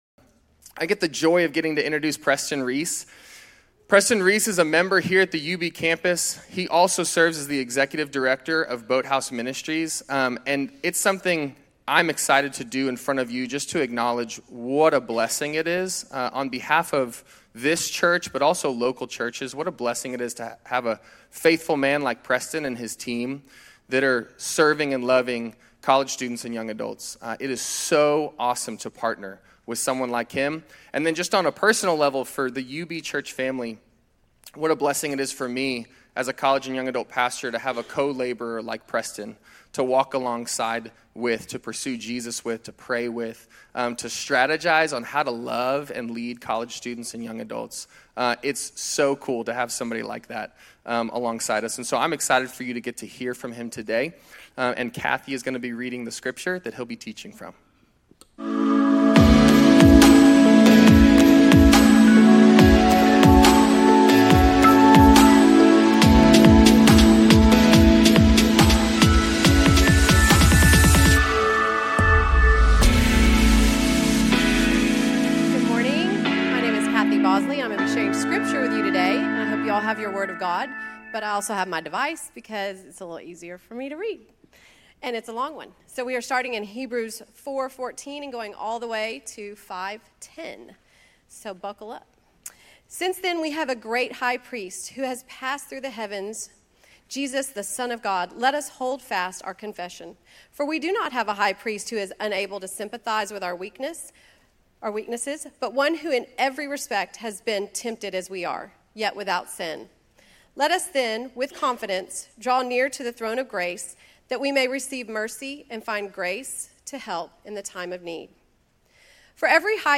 Grace Community Church University Blvd Campus Sermons 10_19 University Blvd Campus Oct 20 2025 | 00:46:20 1x 00:00 / 00:46:20 Subscribe Share RSS Feed Share Link Embed